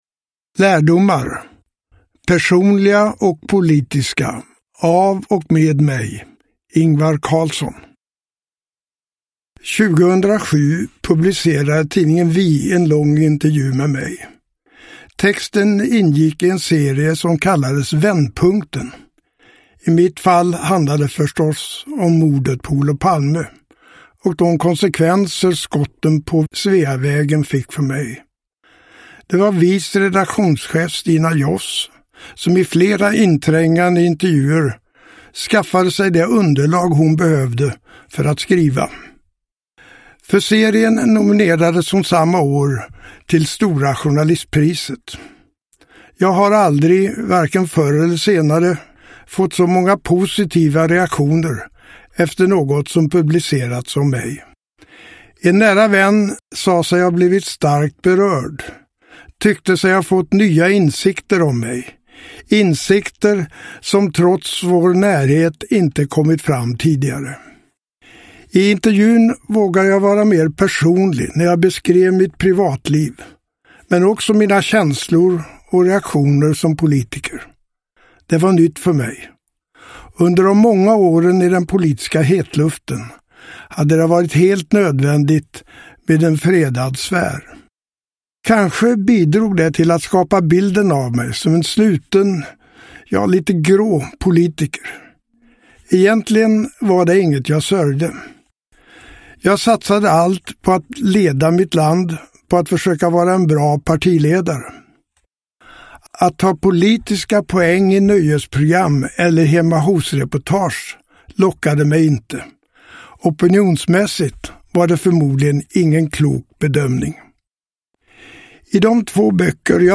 Lärdomar : personliga och politiska – Ljudbok – Laddas ner
Uppläsare: Ingvar Carlsson